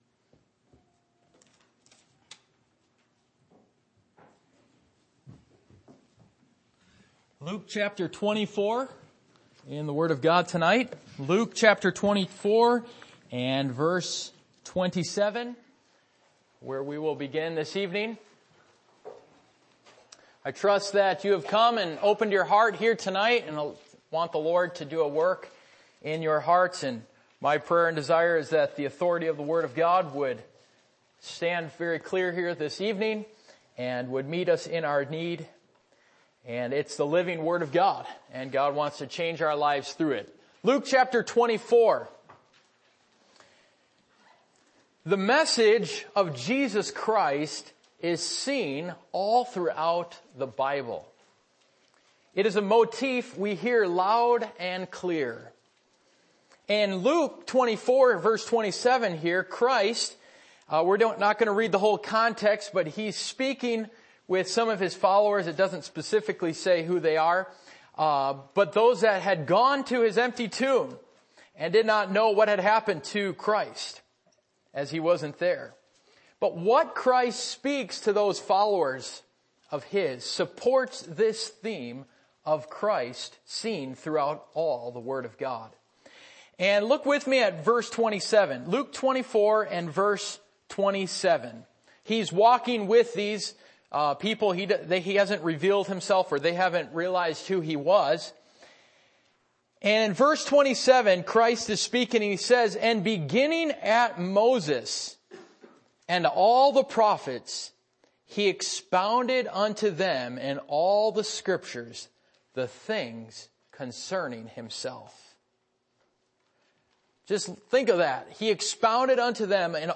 Luke 24:27 Service Type: Midweek Meeting %todo_render% « The Spiritual Battle What Is The Purpose For Your Life?